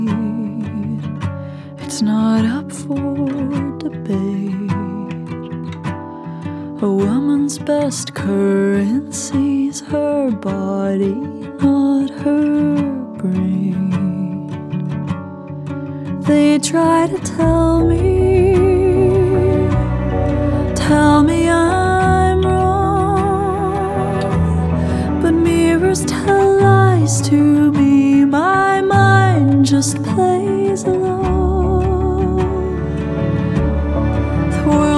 2025-08-07 Жанр: Поп музыка Длительность